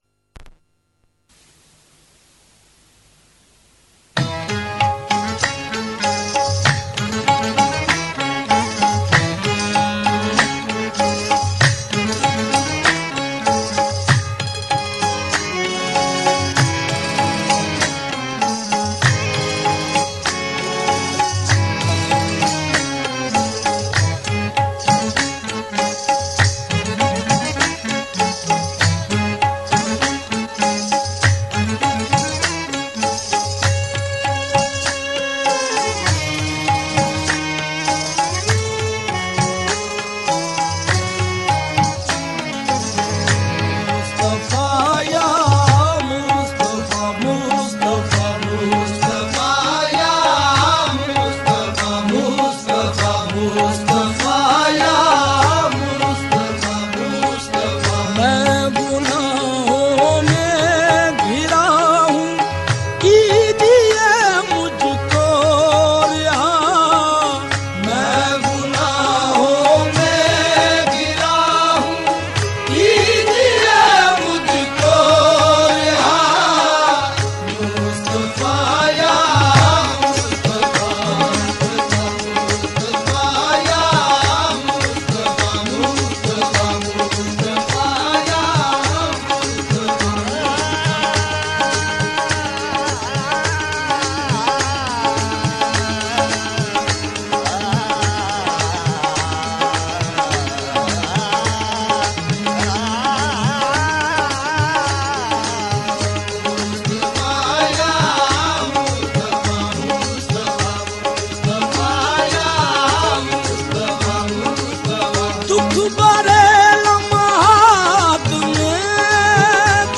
Milad un Nabi – Naat and Naatiya Kalam